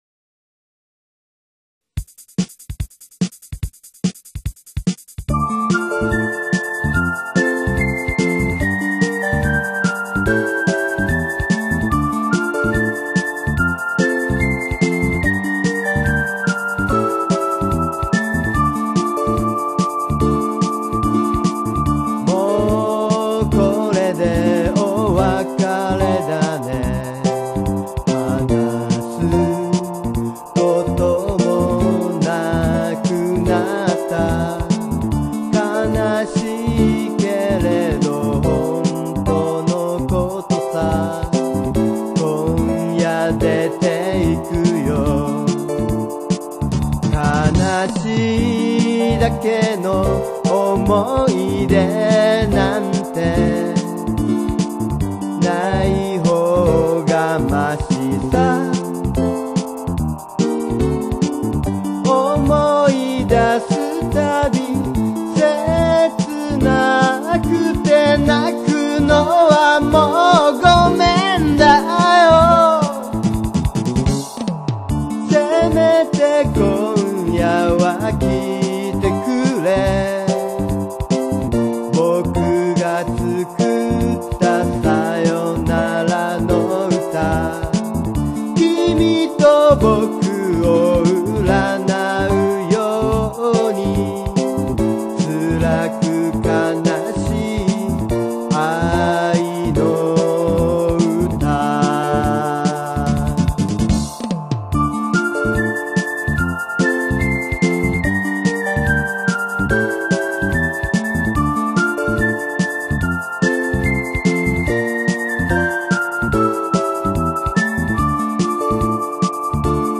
拡張子がwmaのやつはＣＤ音源です